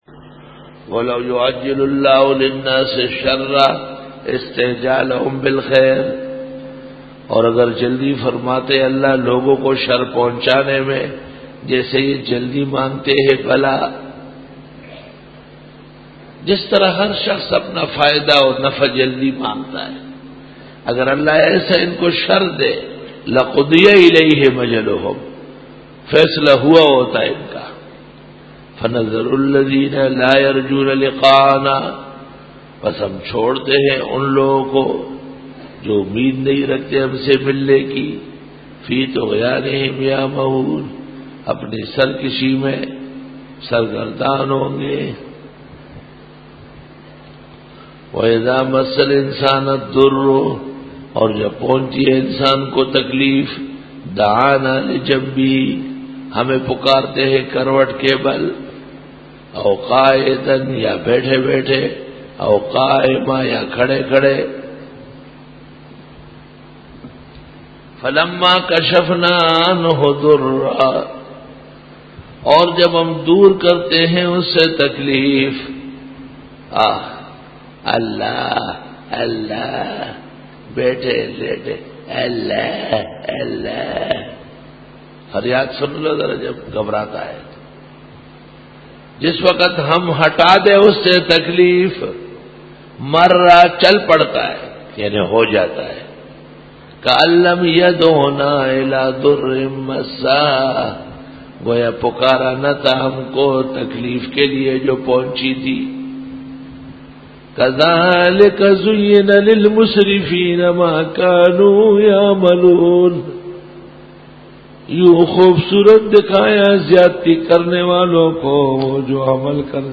Dora-e-Tafseer 2007